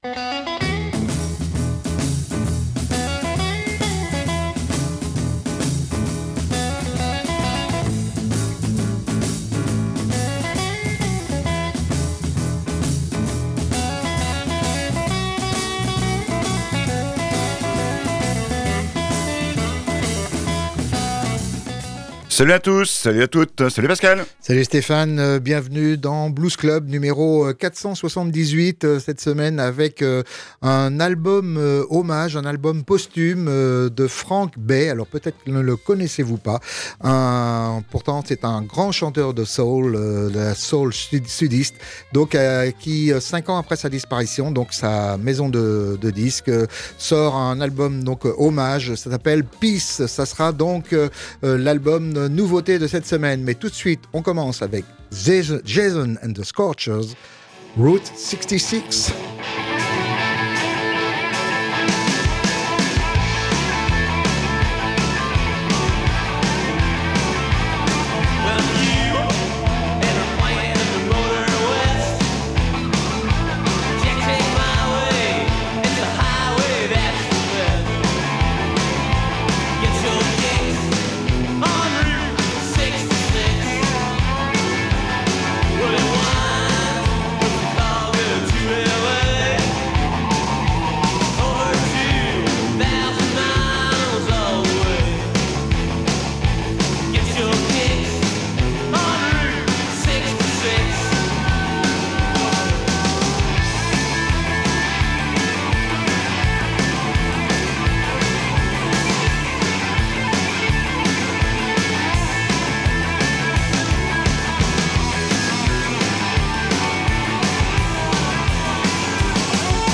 sa voix royale et son style soul